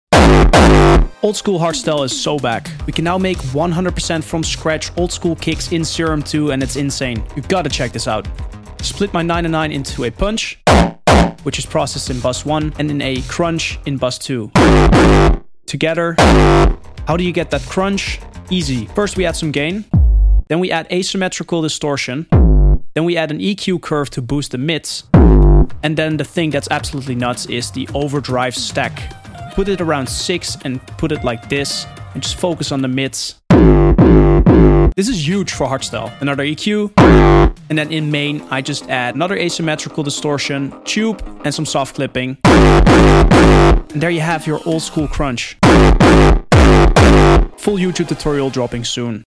Serum 2 Allows For INSANE Sound Effects Free Download